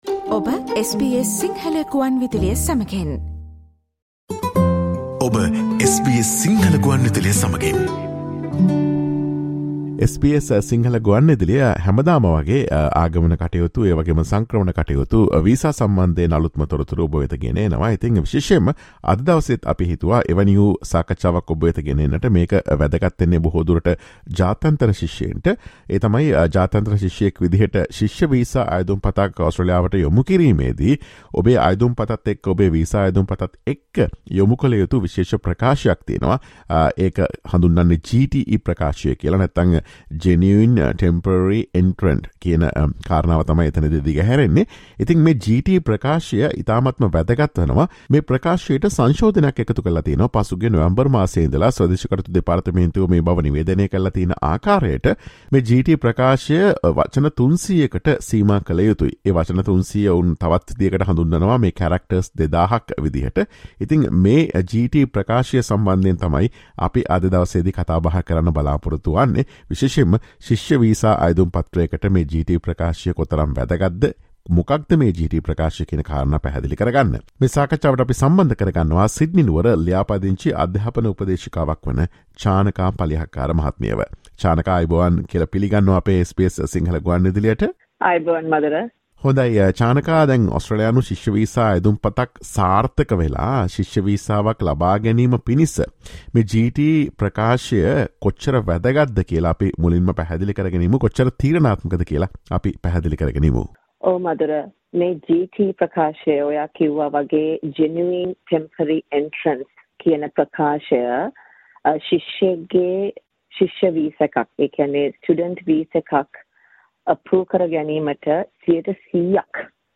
Listen to SBS Sinhala Radio's discussion about the importance of the Genuine Temporary Entrant or GTE statement that must be submitted with the Australian student visa application in order to successfully obtain an Australian student visa.